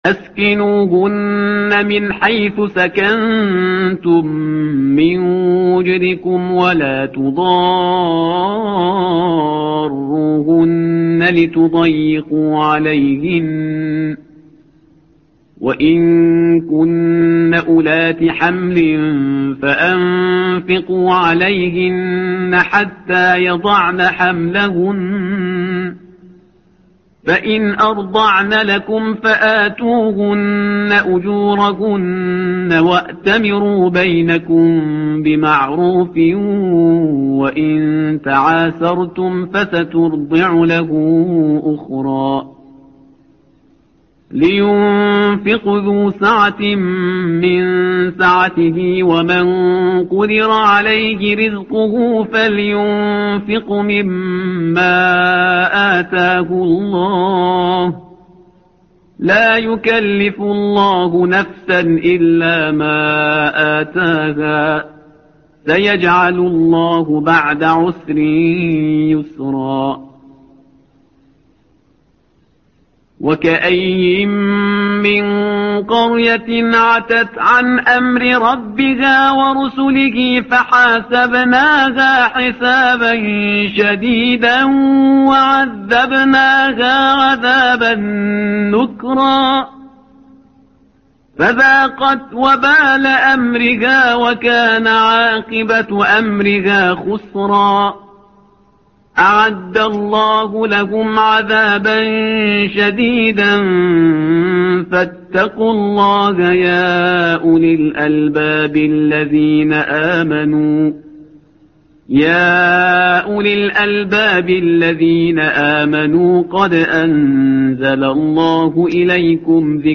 تحميل : الصفحة رقم 559 / القارئ شهريار برهيزكار / القرآن الكريم / موقع يا حسين